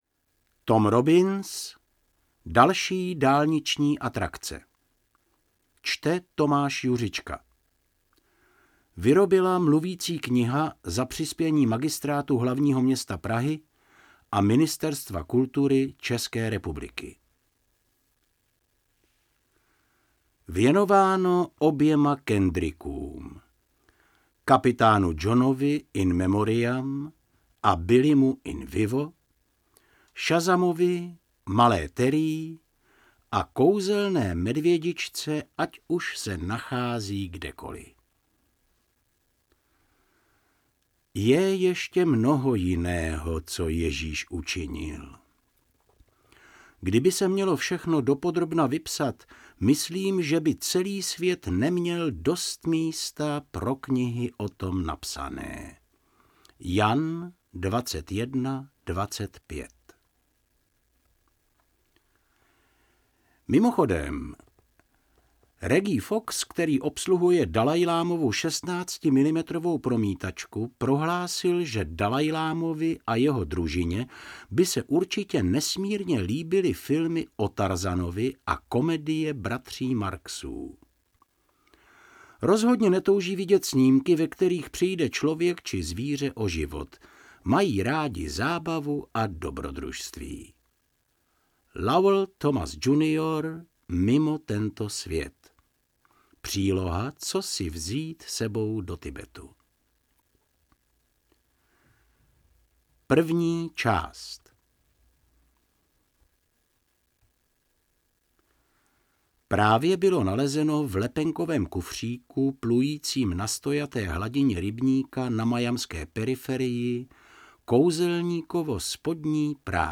Mluvící kniha z.s.